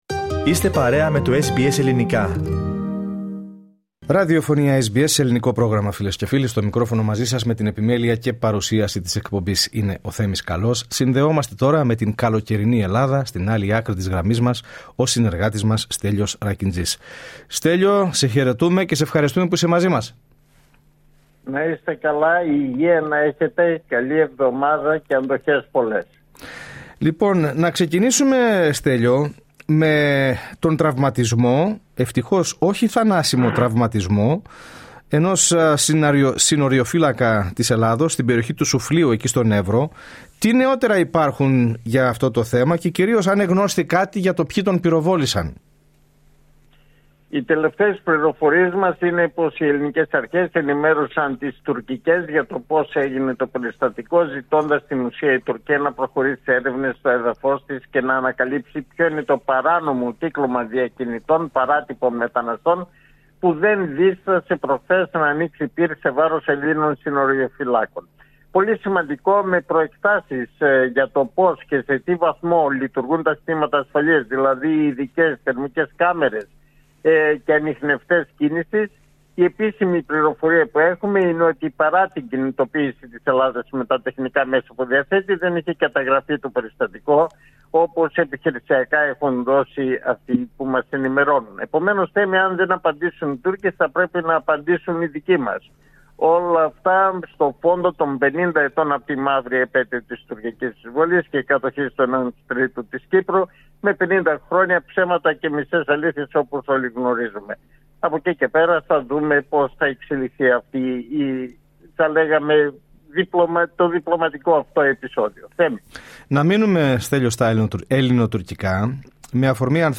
Ιδιαίτερα υψηλή είναι η τουριστική κίνηση στην Ελλάδα φέτος. Κατά πόσο αντέχει η χώρα στην έλευση μεγάλων αριθμών τουριστών; Ακούστε στην ανταπόκριση από Αθήνα